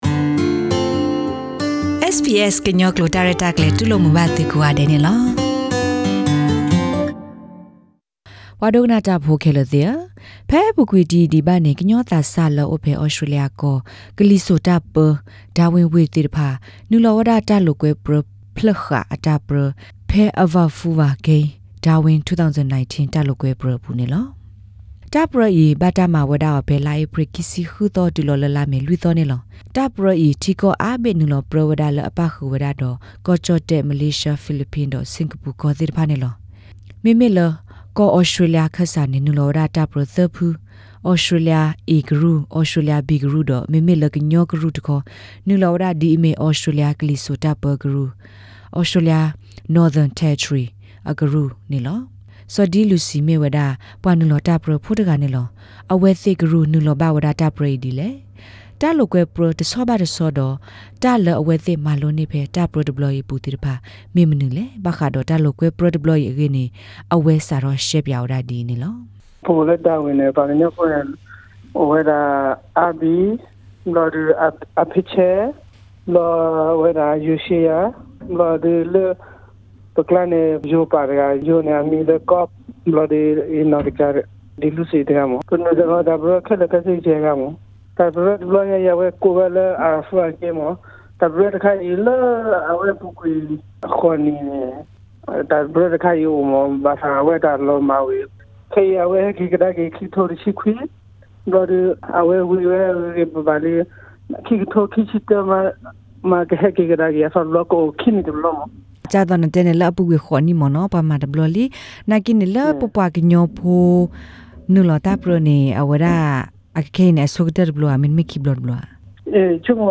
Interview: Meet Australia's Karen Cane Ball Team